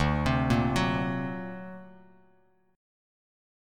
D+7 Chord
Listen to D+7 strummed